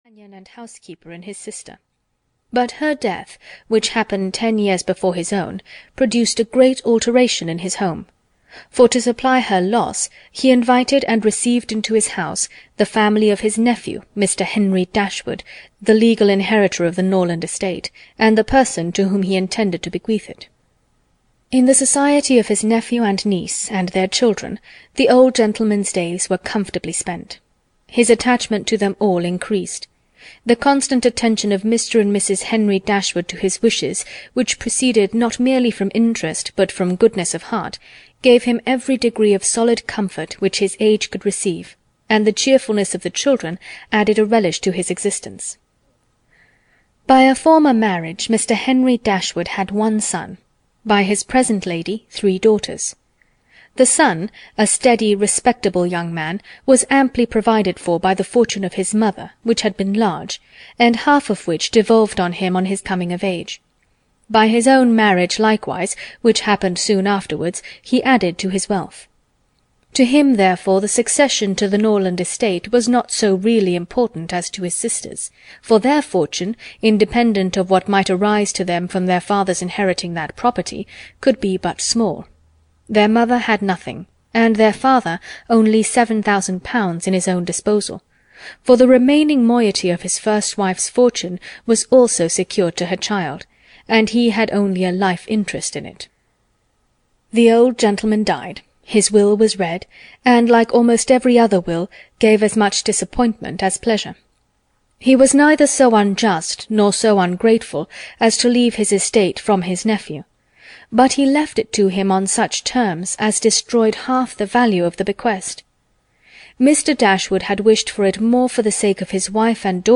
Sense and Sensibility (EN) audiokniha
Ukázka z knihy